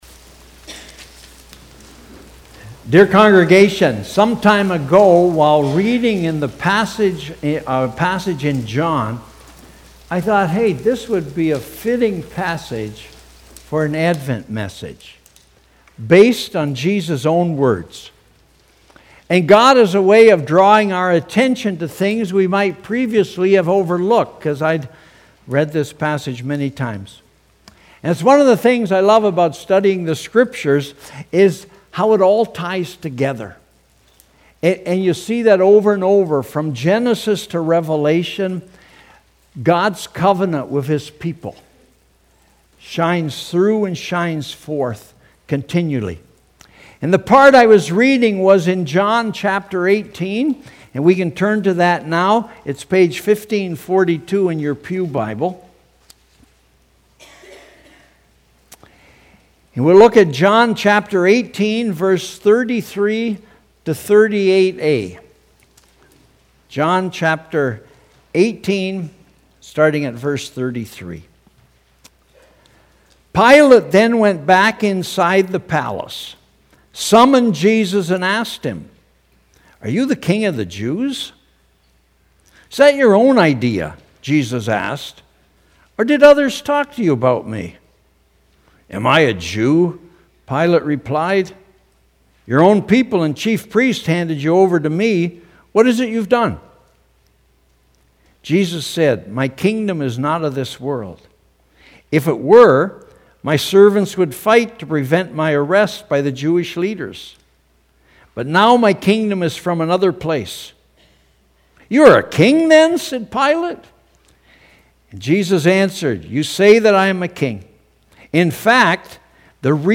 Sermons | Eighth Reformed Church